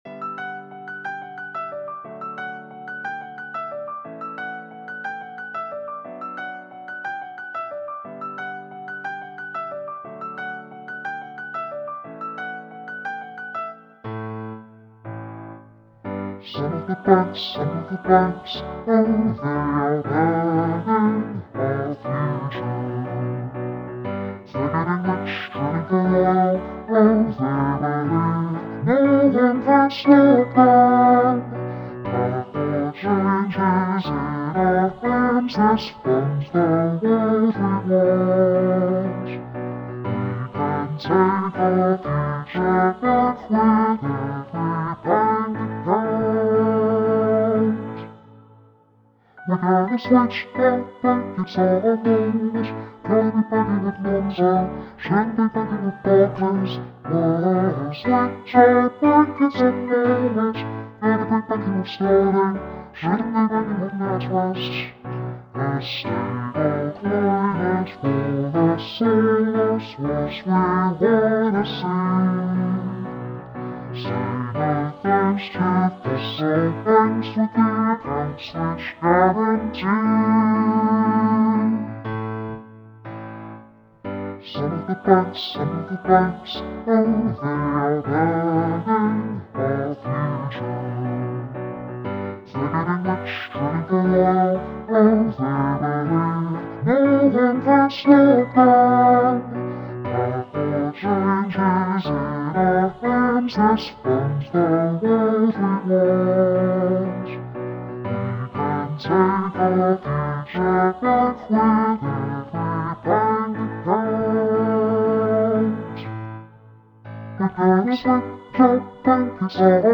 Lower voices:
Were-Gonna-Switch-Our-Bank-mixdown-bass-register.mp3